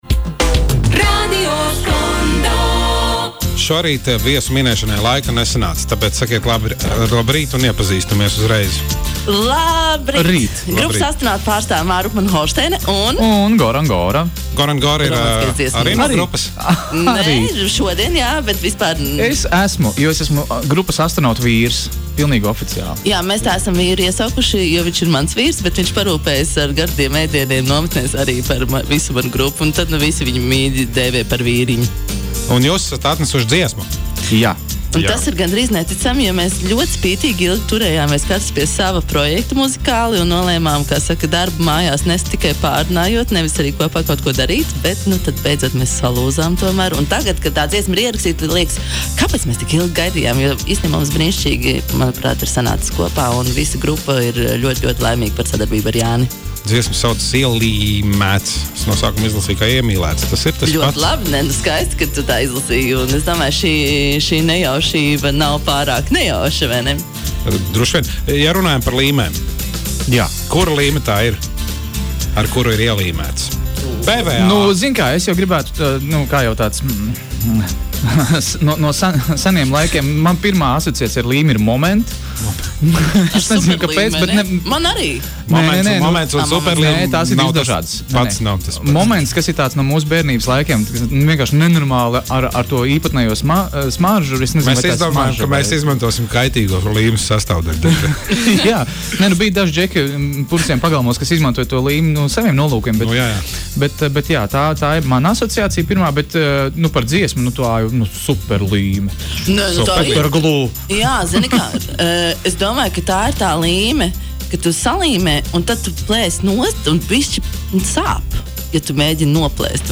Intervija